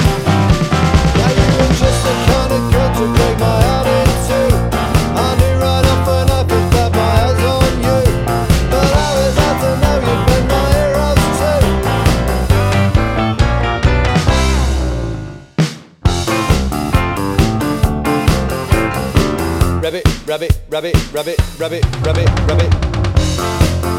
For Solo Singer T.V. Themes 2:23 Buy £1.50